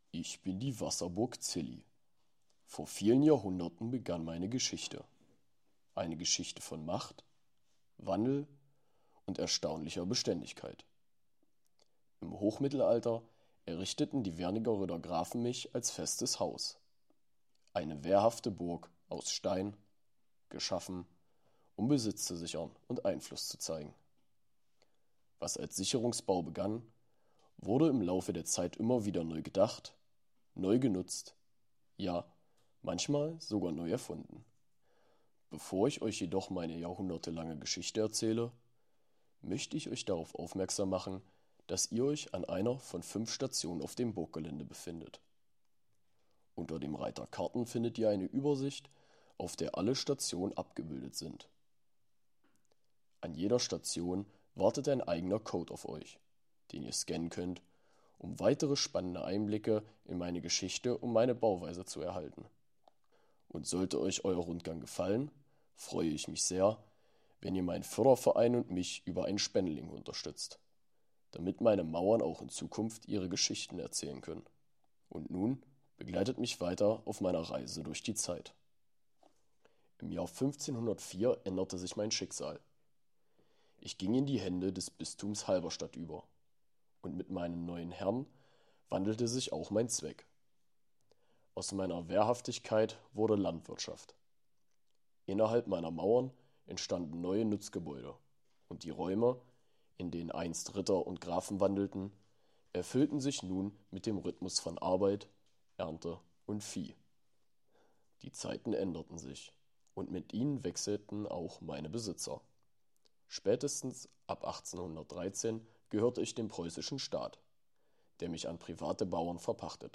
Diese Audiotour begleitet Sie durch Geschichte und Besonderheiten der Anlage.
Einfuehrung-in-die-Audiotour-der-Wasserburg-Zilly-1.mp3